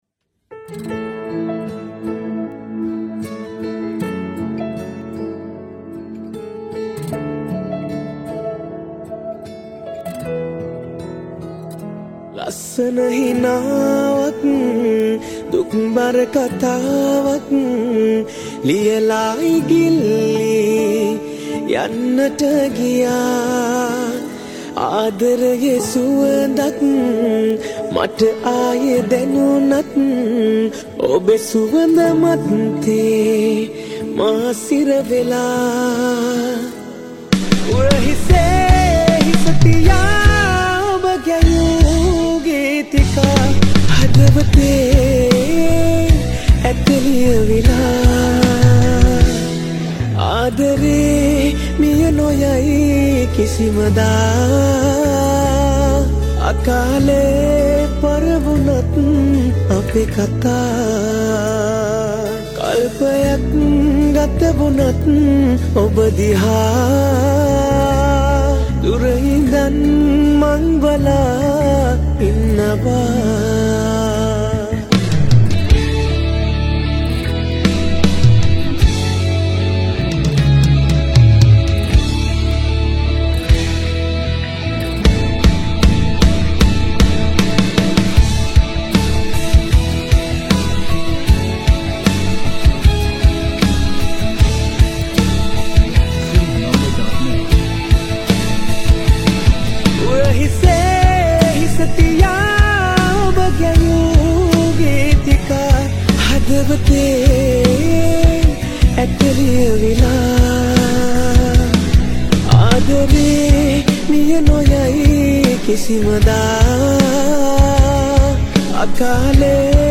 Vocalist
Guitars
Drums & Bass